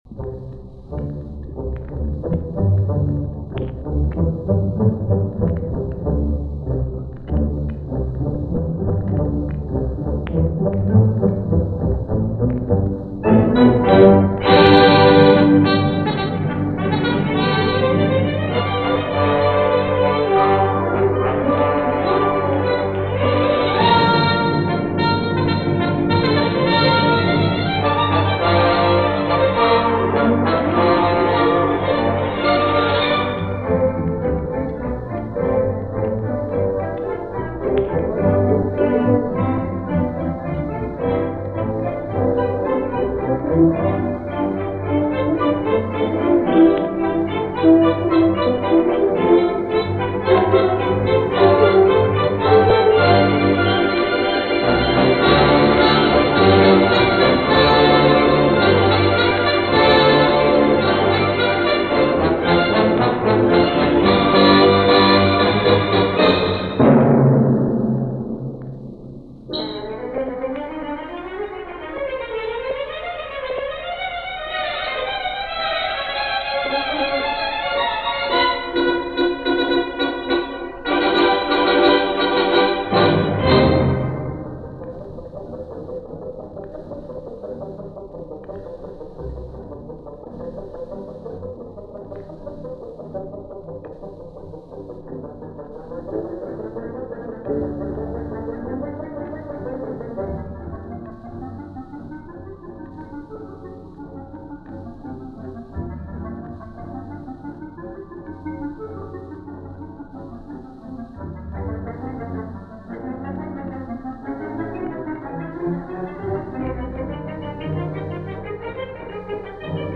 Johan Wagenaar – The Doge of Venice, Suite – Utrecht Municipal Orchestra
From the Radio Nederland transcription service, a broadcast performance featuring the Utrecht municipal Orchestra